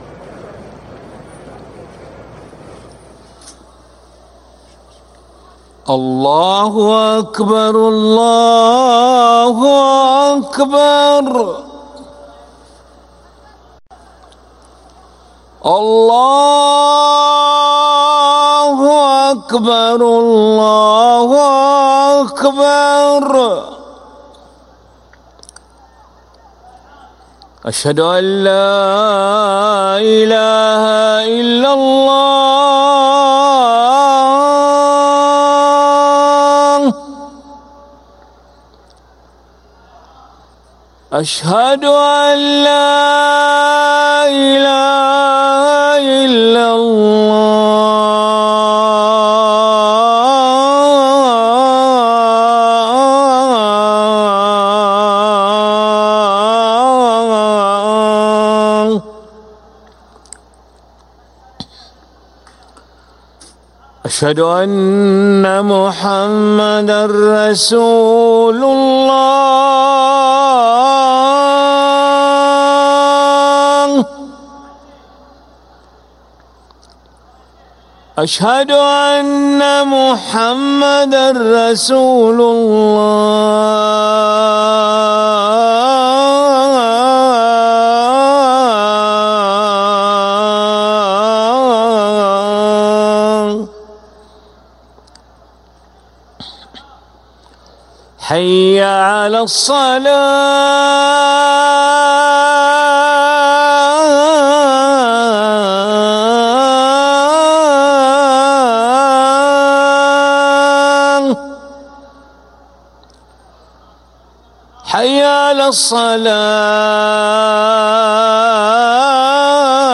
أذان العشاء للمؤذن علي ملا الخميس 25 صفر 1446هـ > ١٤٤٦ 🕋 > ركن الأذان 🕋 > المزيد - تلاوات الحرمين